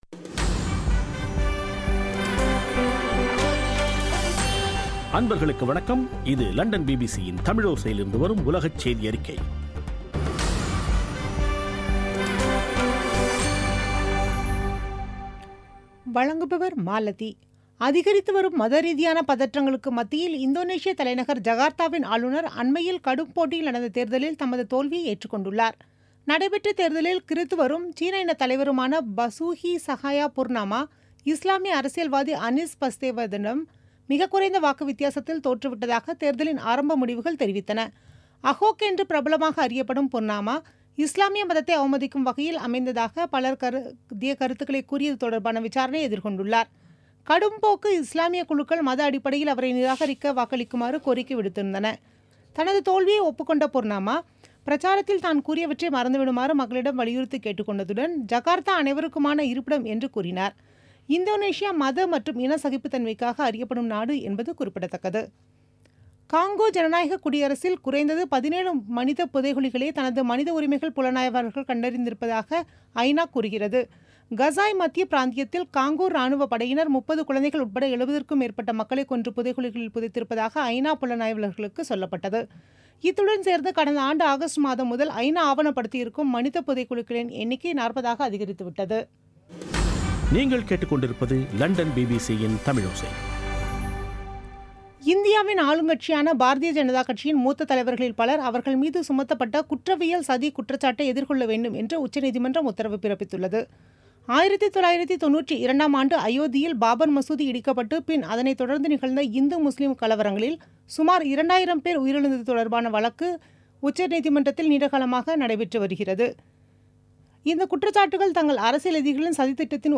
பிபிசி தமிழோசை செய்தியறிக்கை (19/04/2017)